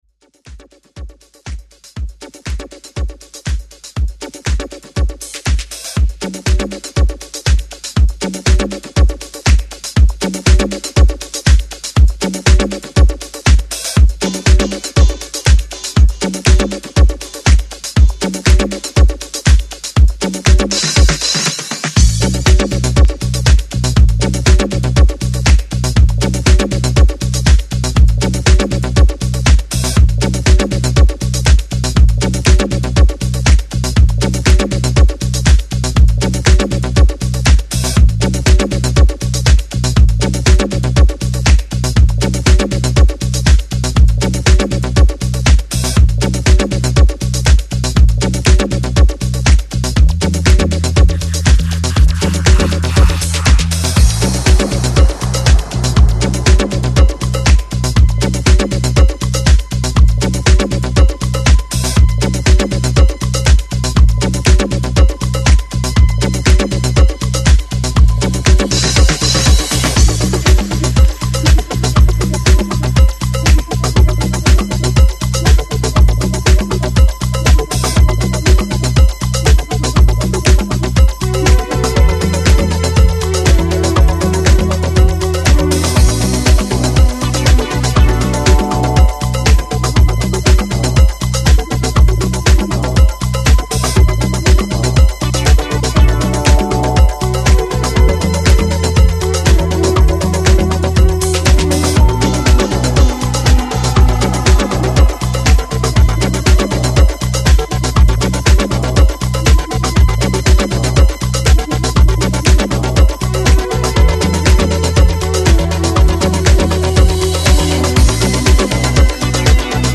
funky Chic style dancefloor-filling filter-disco sound
instrumental